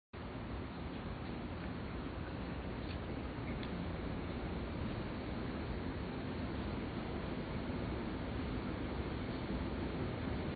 松山區南京東路四段53巷住宅外
均能音量: 51.9 dBA 最大音量: 62.8 dBA 地點類型: 住宅外 寧靜程度: 5分 (1分 – 非常不寧靜，5分 – 非常寧靜)
說明描述: 平靜 聲音類型: 馬達聲